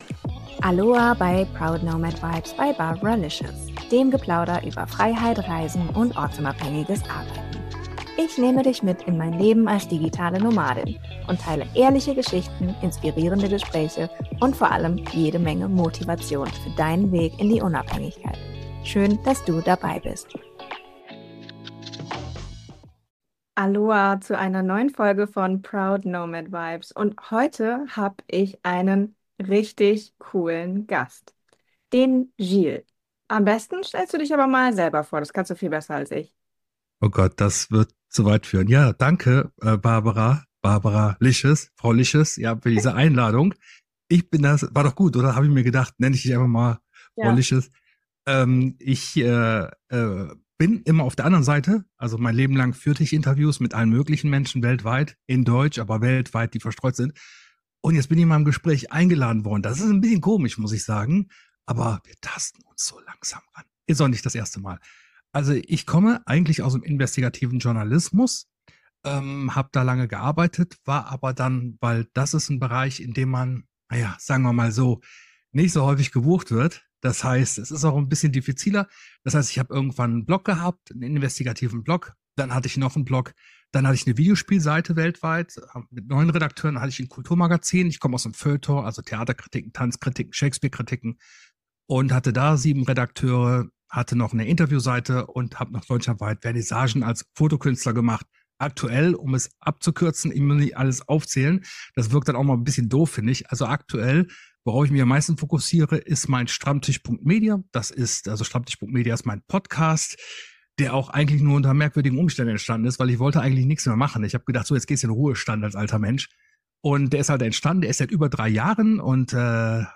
Mut zum Nicht-Normalsein: Ein Gespräch